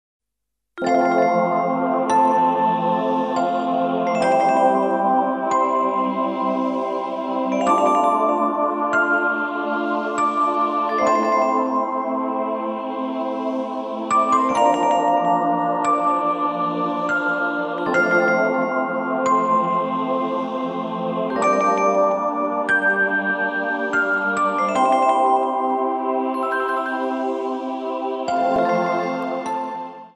Piękna harmonijna muzyka do masżu.